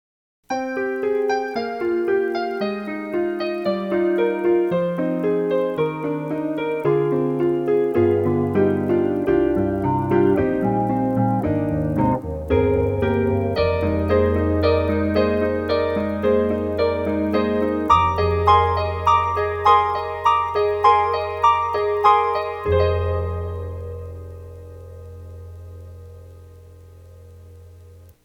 特集：徹底比較！DTM音源ピアノ音色聴き比べ - S-studio2
041_FM_Piano
E-MU_UltraProteus_041_FM_Piano.mp3